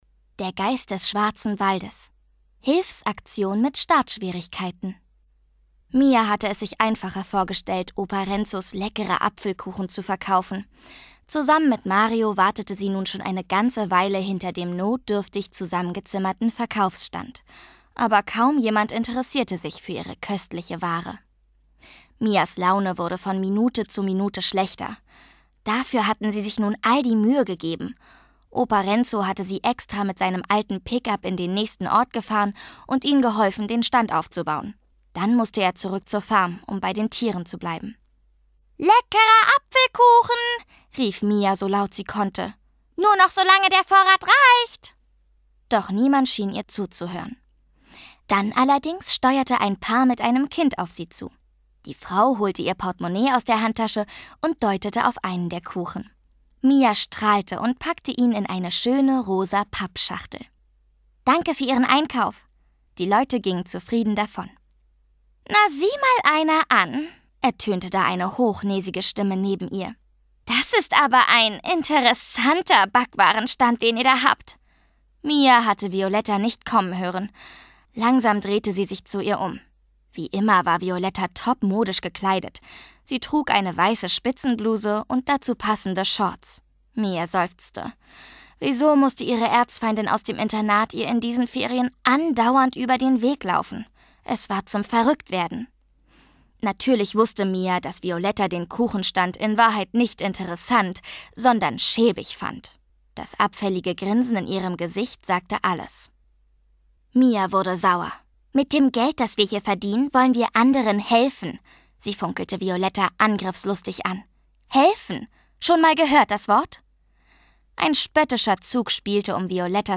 Schlagworte Abenteuer • Centopia • Einhorn • Einhorn / Einhörner; Kinder-/Jugendliteratur • Einhorn; Kinder-/Jugendliteratur • Elfen • Elfen; Kinder-/Jugendliteratur • Fantasie • Fantasy; Kinder-/Jugendliteratur • Florenz • Freunde • Freundschaft • Hörbuch • Hörbuch; Lesung für Kinder/Jugendliche • Internat • Kinder • Lesung • Mädchen • Mädchen; Kinder-/Jugendliteratur • Magie • Paradies • Rettung • Serie • Zauberei